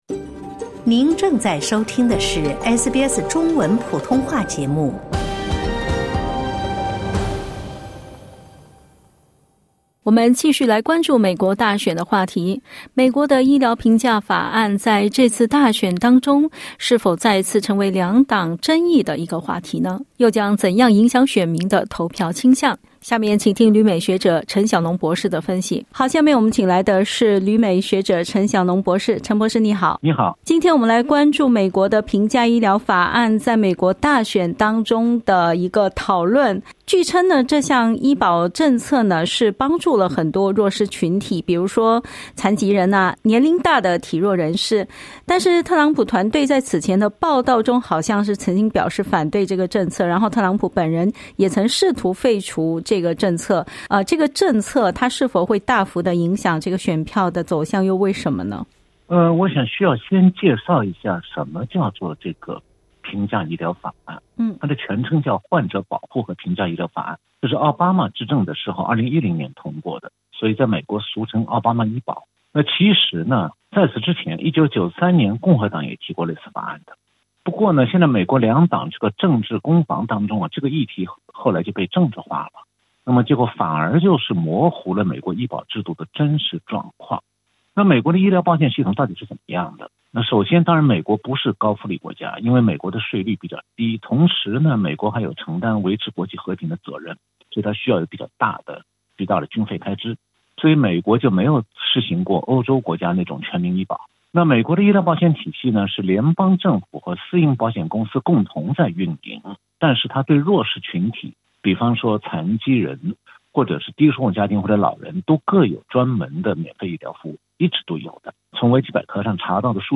当前美国的医疗法案时常在两党之间引起分歧，原因何在？点击音频收听详细采访